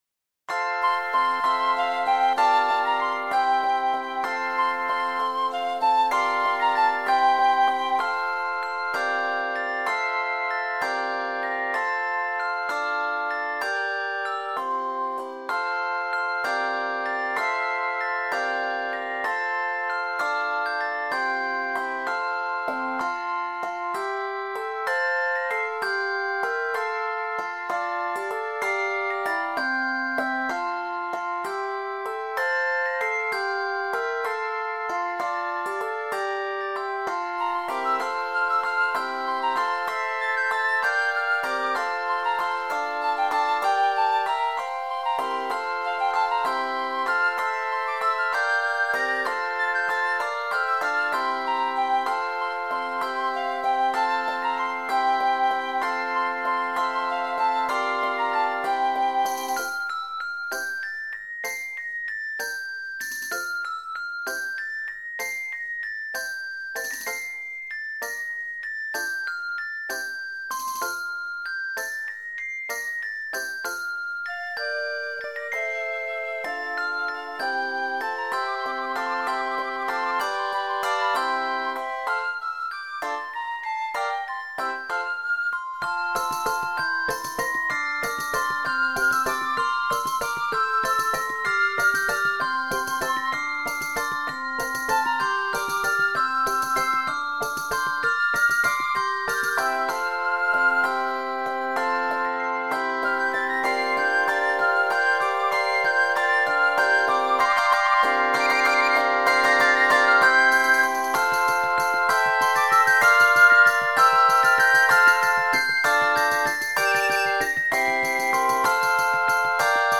This quiet, reflective arrangement